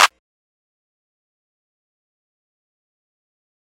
Clap [ Luger Slap ].wav